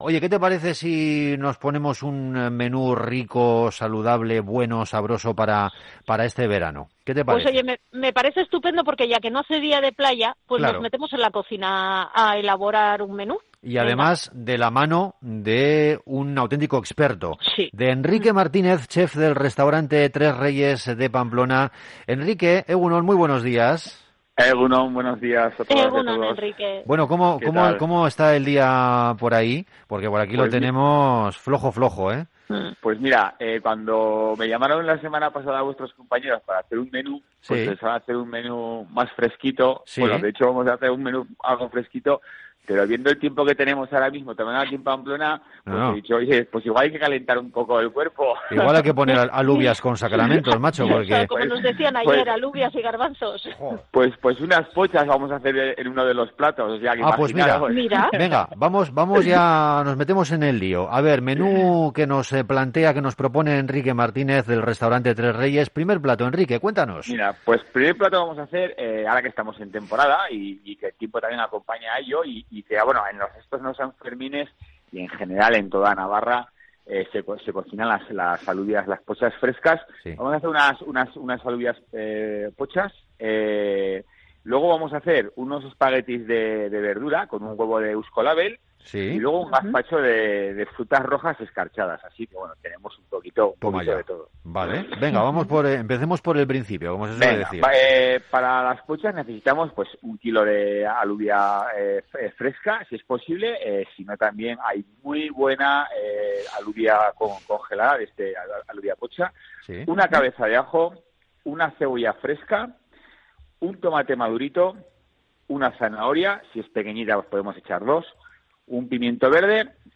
Morning show conectado a la calle y omnipresente en la red.